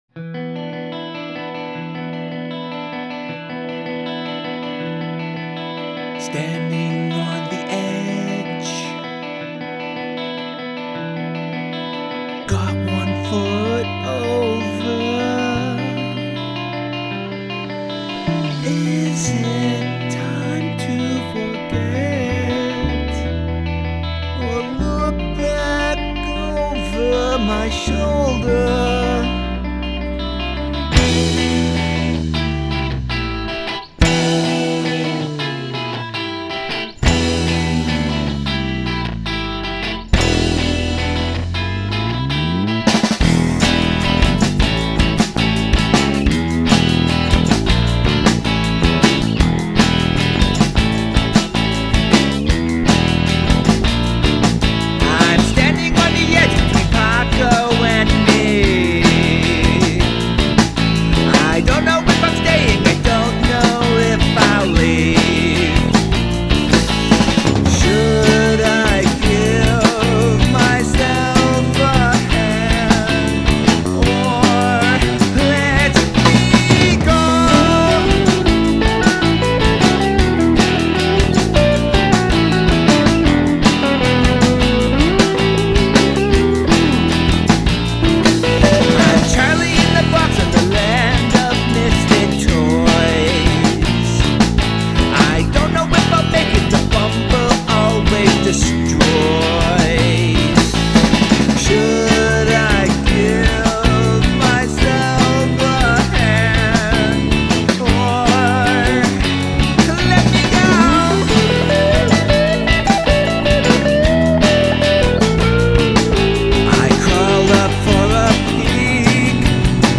Moody, dark